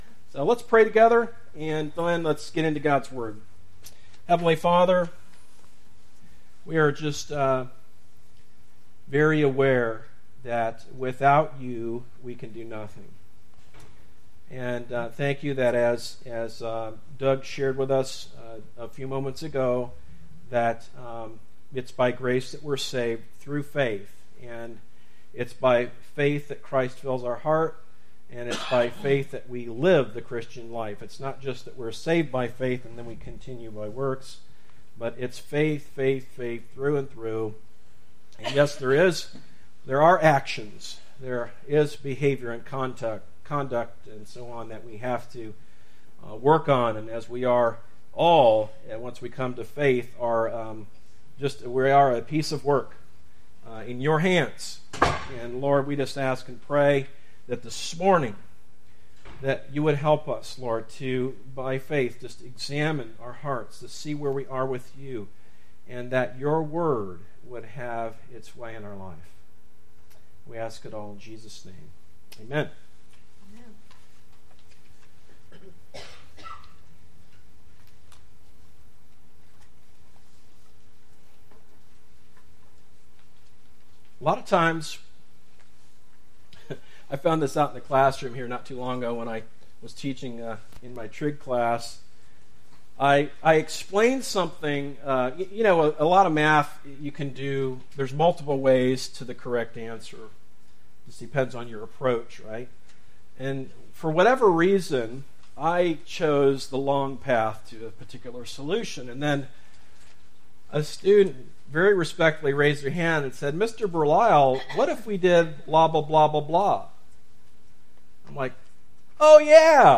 Sermons - Darby Creek Church - Galloway, OH